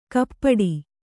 ♪ kappaḍi